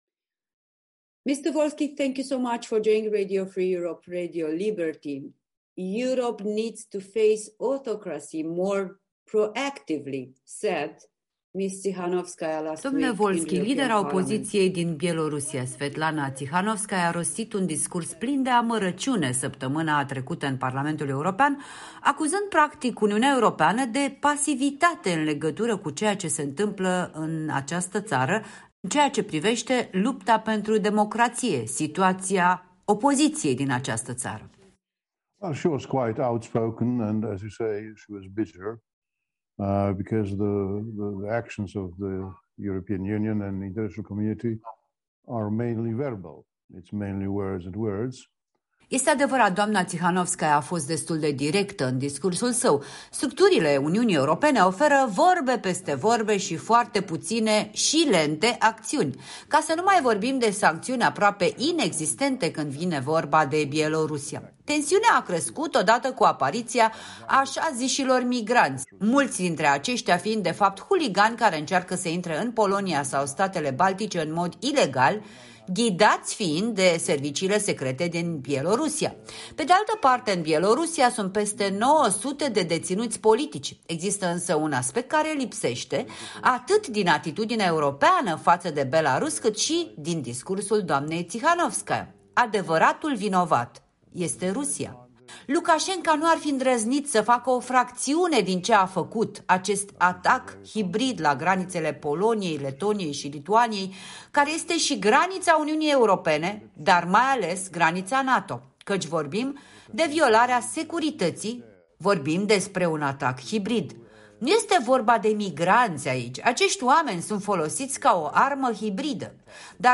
Interviu cu Jacek Saryusz-Wolski